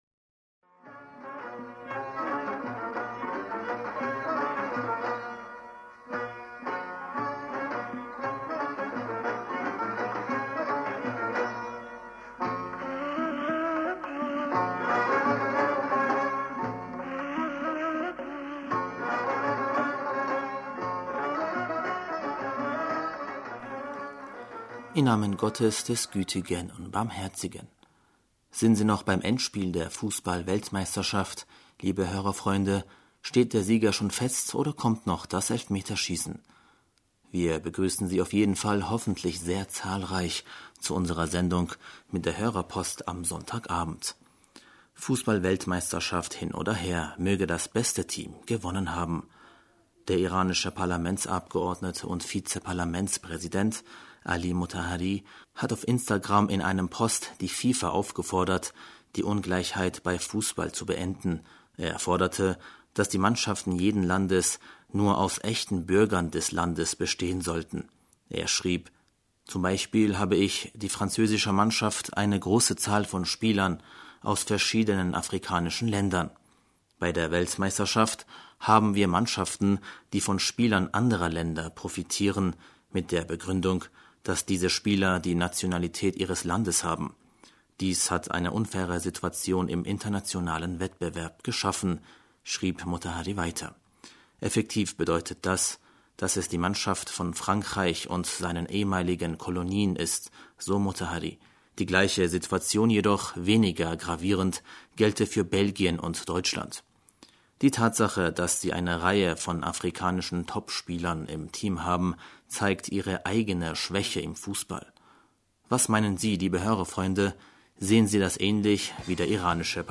Hörerpostsendung
Wir begrüßen Sie auf jeden Fall hoffentlich sehr zahlreich zu unserer Sendung mit der Hörerpost am Sonntagabend.